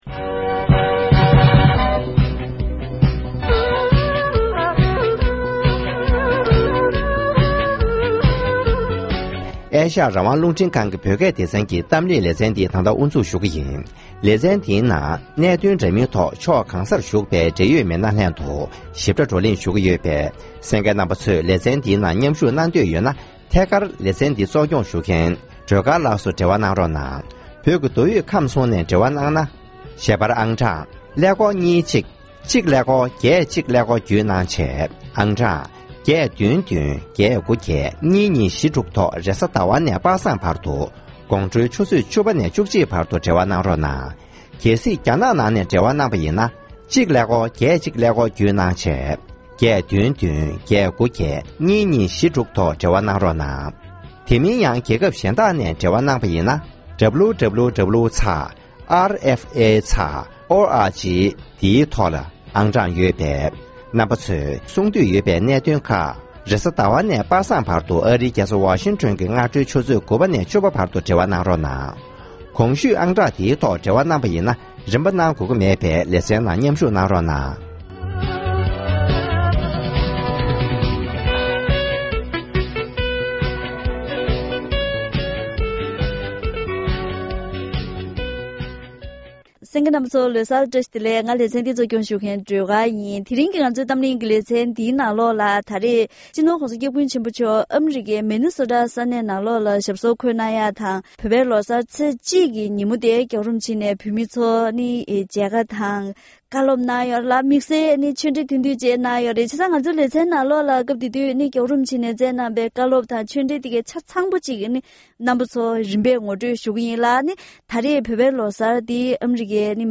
བོད་ཀྱི་ལོ་གསར་ལ་བཀའ་སློབ།
ཨ་རིའི་ནུབ་བྱང་མངའ་སྡེ་མི་ནི་སོ་ཊའི་བོད་མིའི་ལོ་གསར་གྱི་མཛོད་སྒོའི་ཐོག་སྤྱི་ནོར་༸གོང་ས་༸སྐྱབས་མགོན་ཆེན་པོ་མཆོག་ནས་དམིགས་བསལ་བཀའ་སློབ་དང་གསུང་ཆོས་སྩལ་གནང་མཛད་པའི་གནས་ཚུལ།